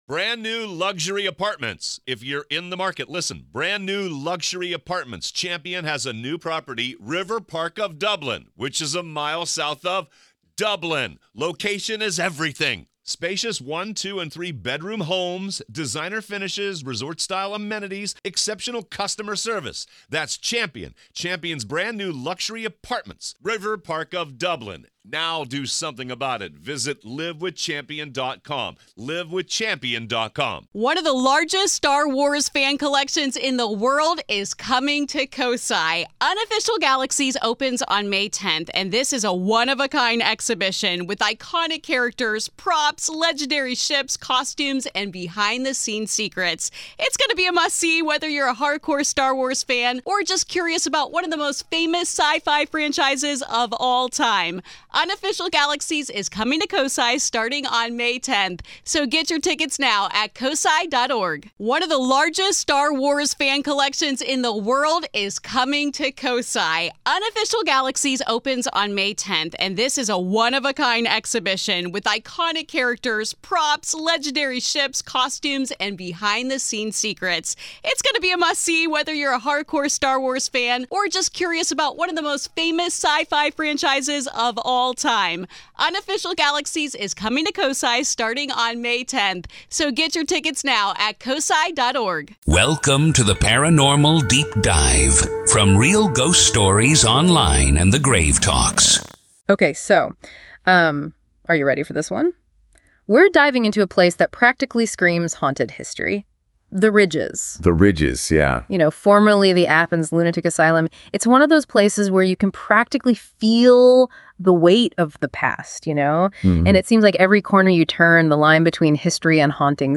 Deep Dive DISCUSSION!